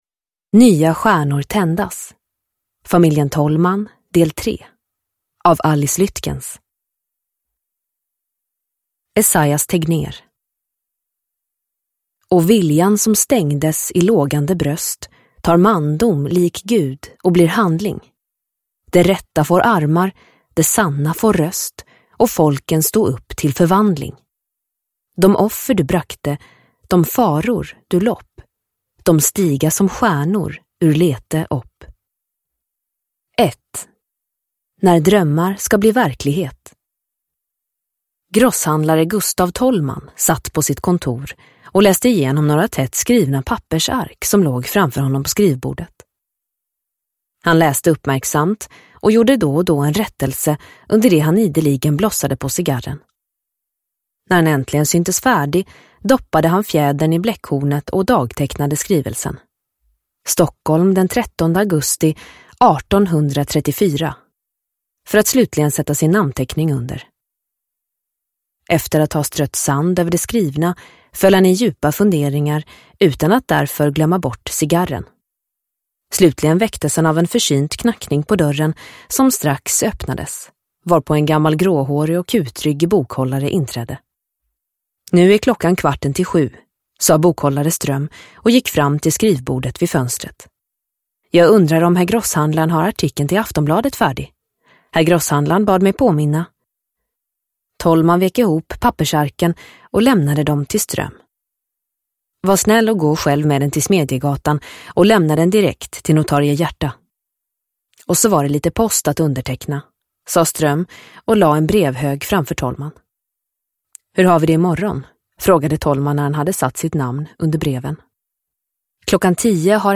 Nya stjärnor tändas – Ljudbok – Laddas ner